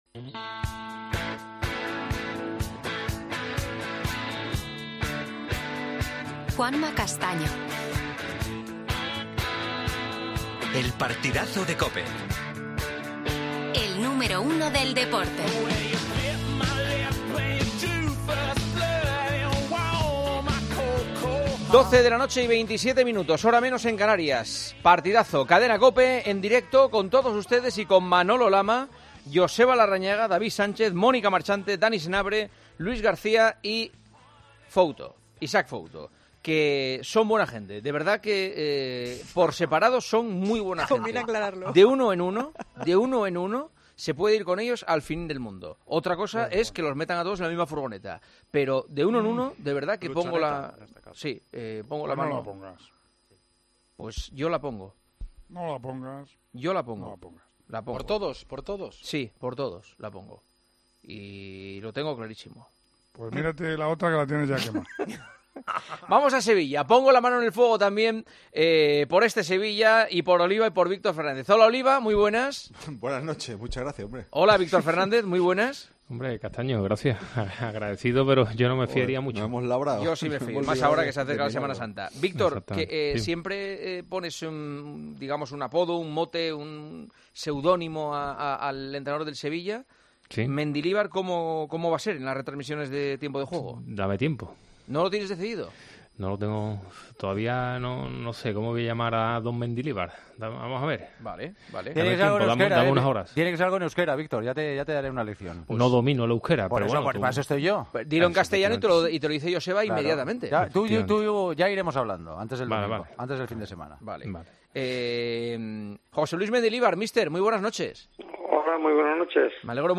AUDIO: Entrevista al nuevo entrenador del Sevilla, José Luis Mendilibar.